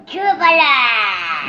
• Качество: 130, Stereo
забавный голос
Забавный голос то ли тролля, то ли гнома